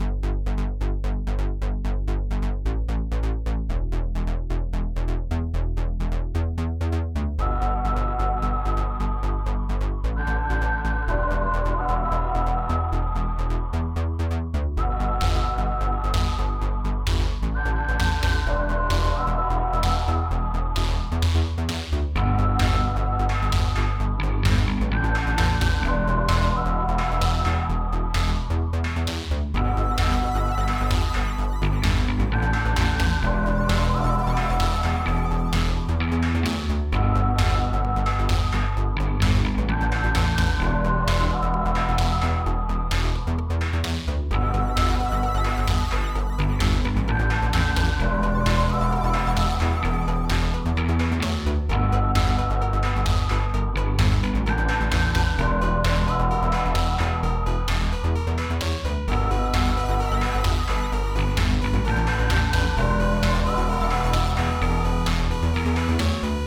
i need to rerecord the bass, but the tone of it turned out so well, I finally seem to be getting the hang of it
the plucky synth towards the end seems a bit much though, i need to clean it up